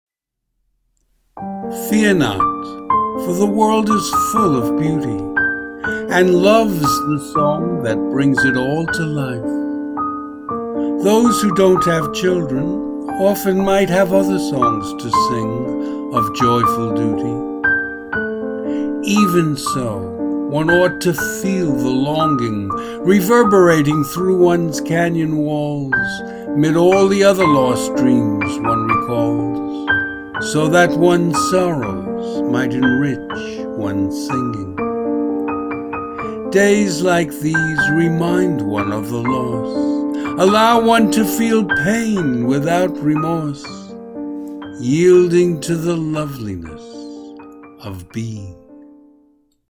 Audio and Video Music: